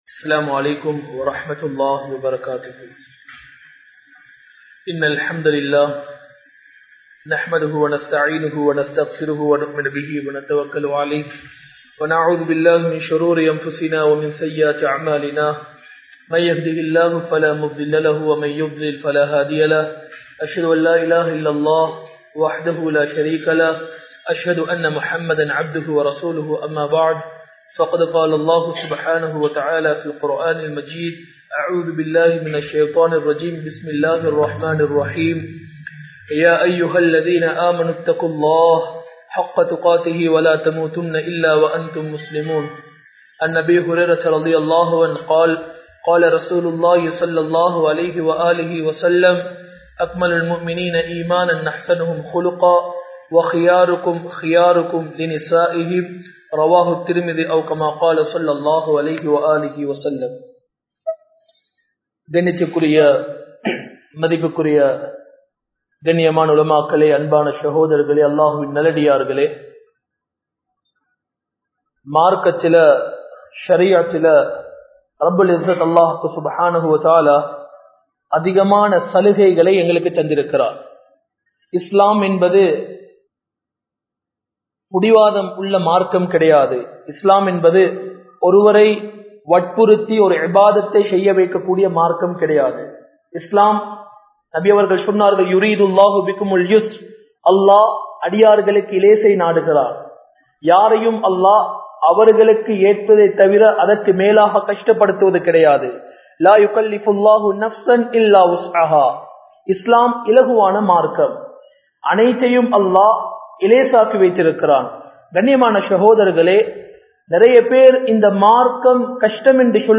Thirumanaththil Haraamkal (திருமணத்தில் ஹராம்கள்) | Audio Bayans | All Ceylon Muslim Youth Community | Addalaichenai
Majma Ul Khairah Jumua Masjith (Nimal Road)